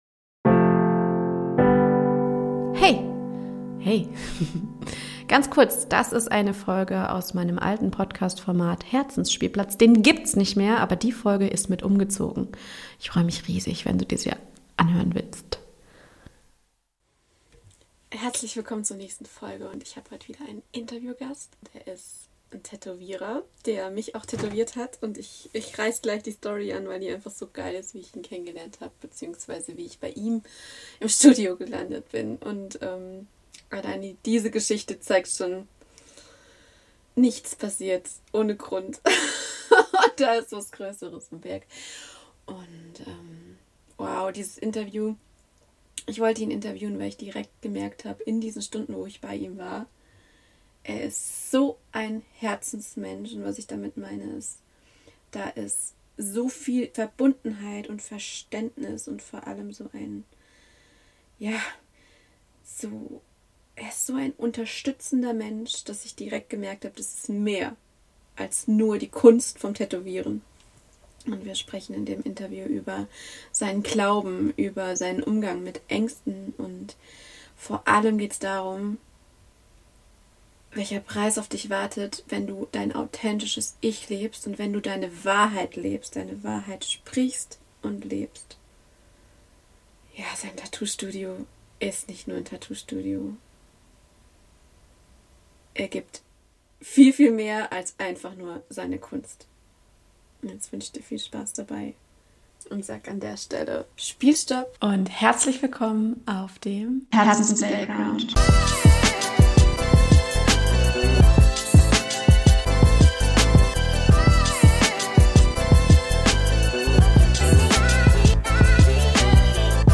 Heute mit Interviewgast.